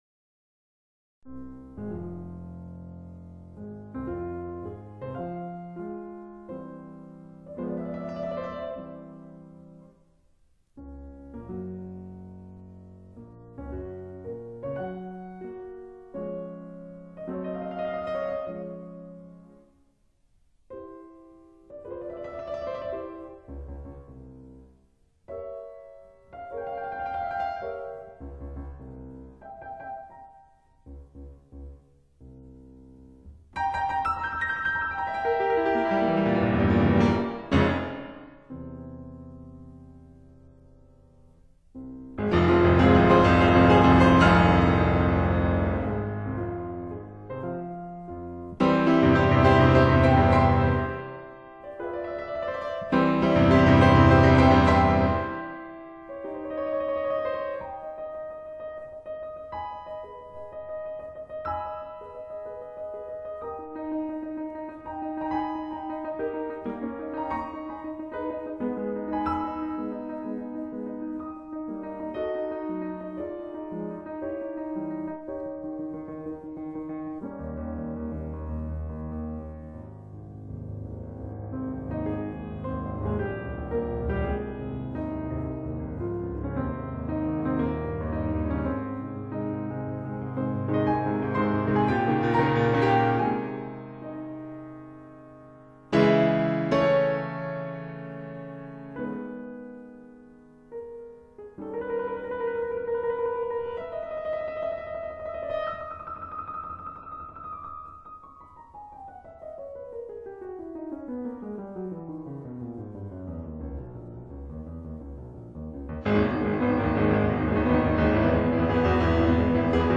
Piano Sonatas
单听第一首的音色与空气感已靓到吓人，动态之强更是无人性，更令人惊讶的是立体感与质感的浓烈！